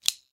锁刃刀 " 锁刃刀打开04
描述：口袋里的刀，有刀片展开的声音。
标签： 单击 单次
声道立体声